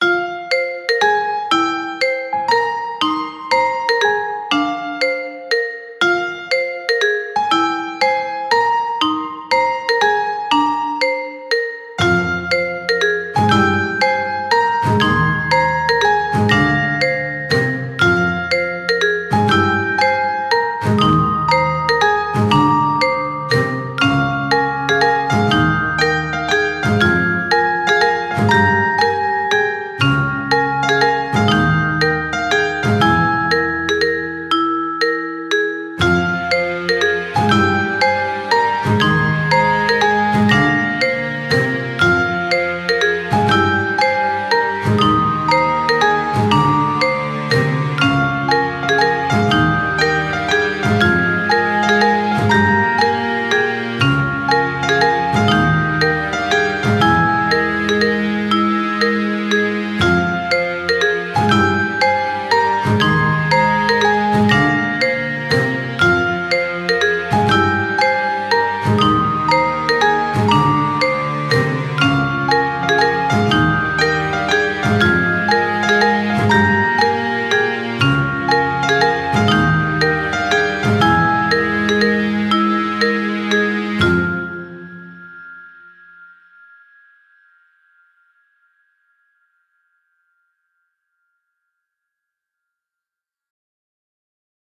Mysterious background track.